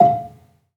Gambang-F4-f.wav